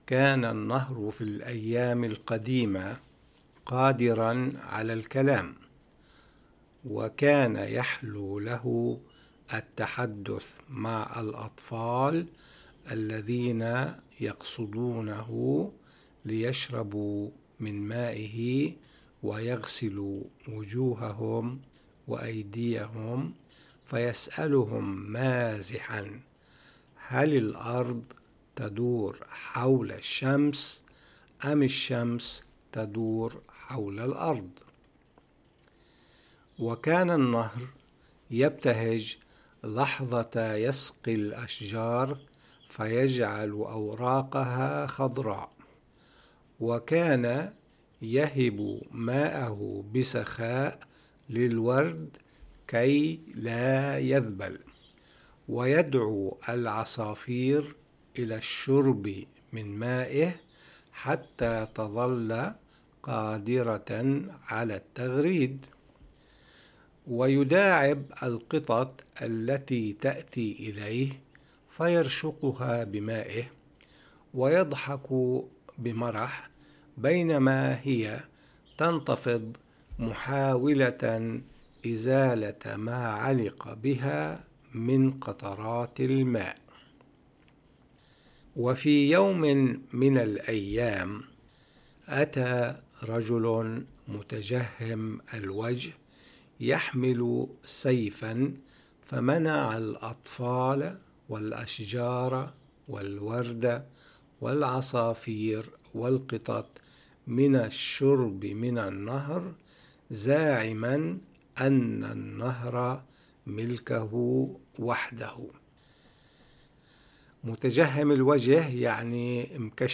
بودكاست: قصة للصغار